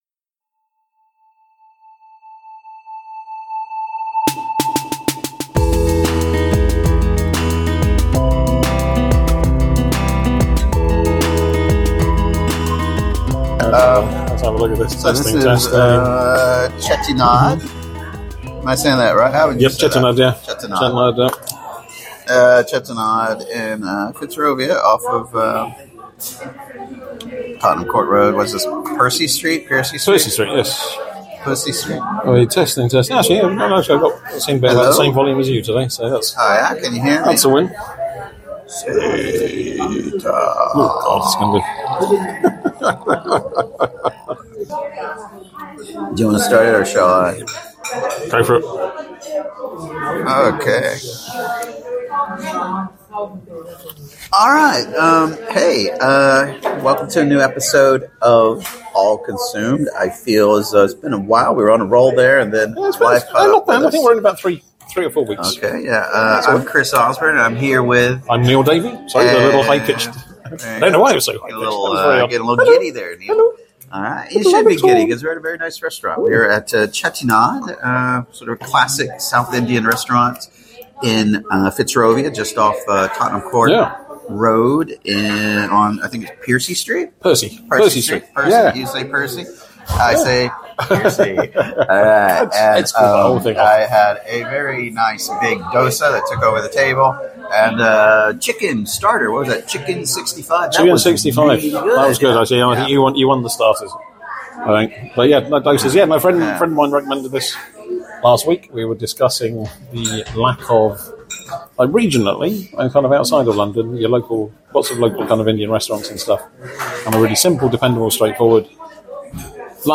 catch up at south Indian “village restaurant” Chettinad off Tottenham Court Road to record this episode of All Consumed